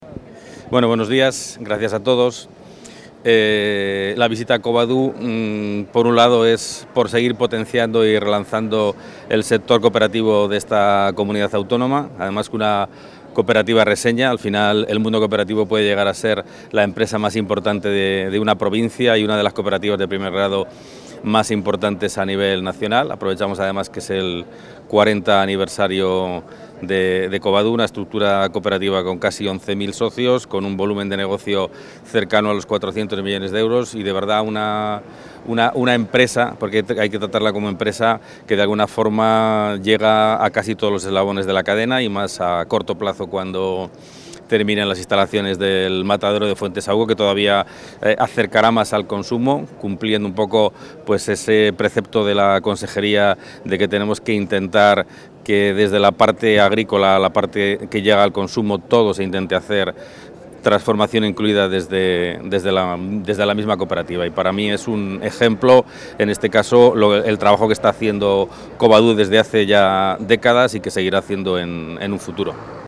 Intervención del consejero.
Gerardo Dueñas ha visitado la cooperativa zamorana Cobadu en su cuarenta aniversario, donde ha reiterado el apoyo de la Junta de Castilla y León a estas entidades